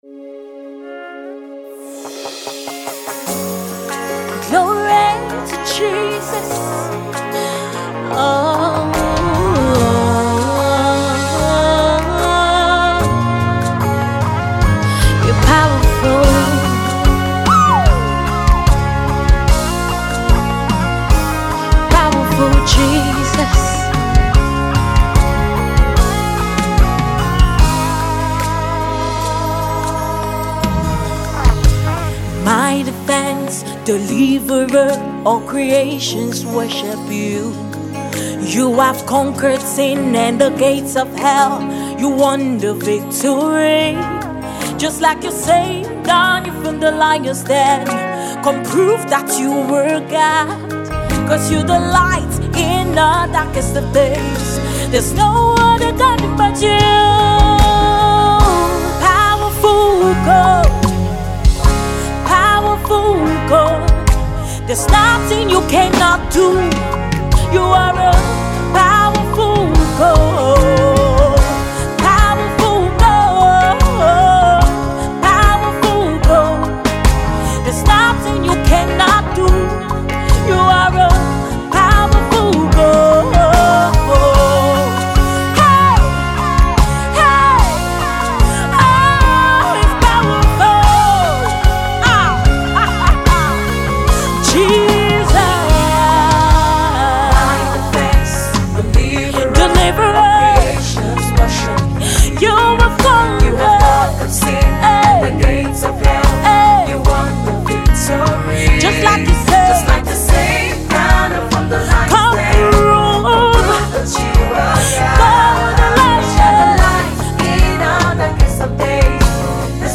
a solemn and yet bold declaration